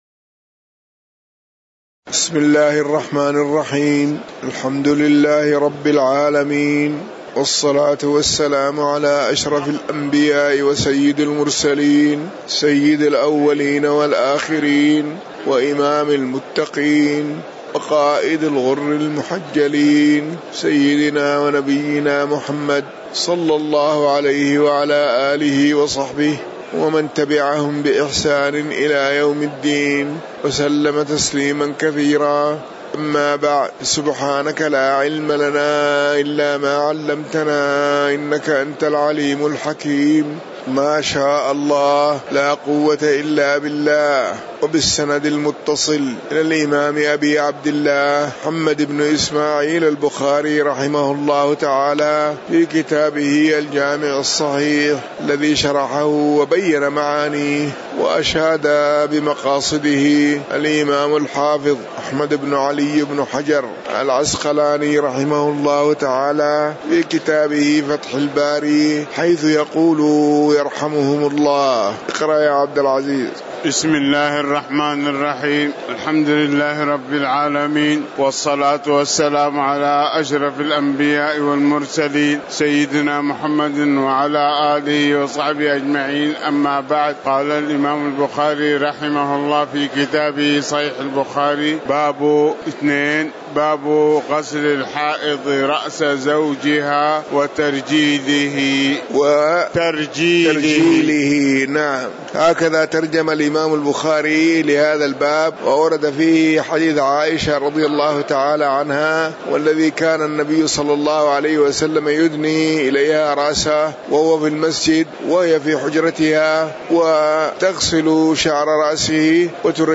تاريخ النشر ٢٢ جمادى الأولى ١٤٤٠ هـ المكان: المسجد النبوي الشيخ